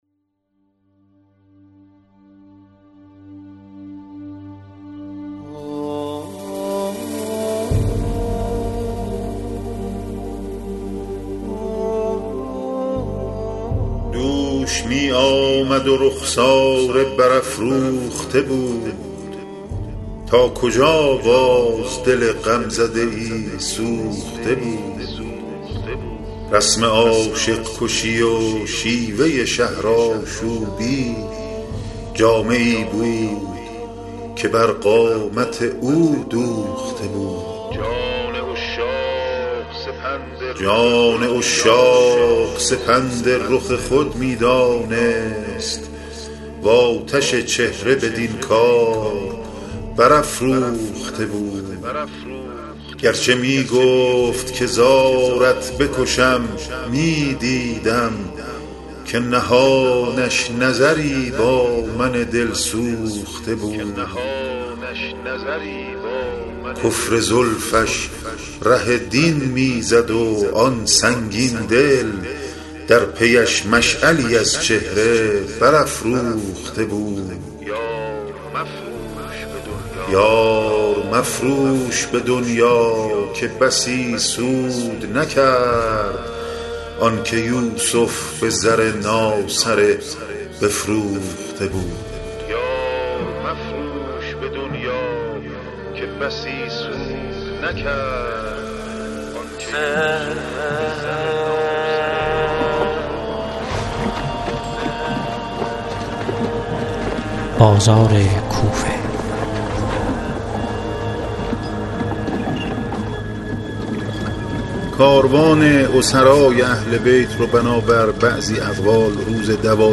✦ پژوهشگر و راوی
✦ نویسنده و گوینده متن‌های ادبی